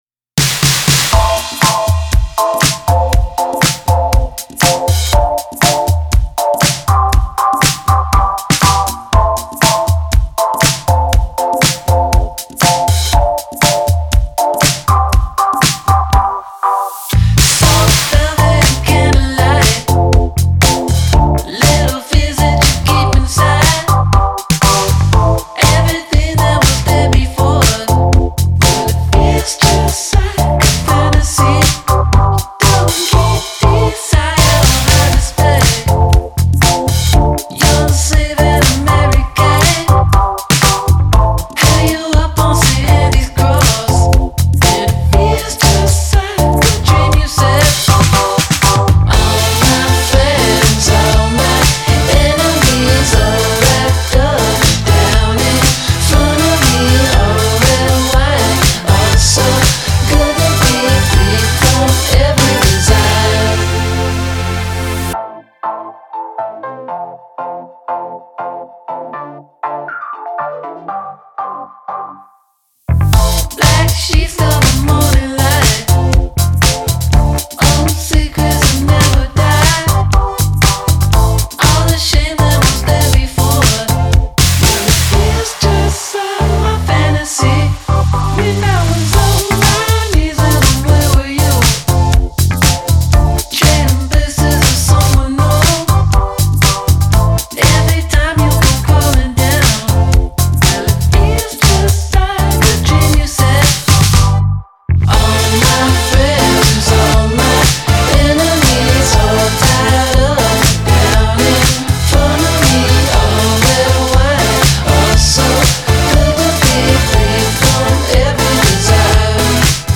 highly danceable, lo-fi filtered tunes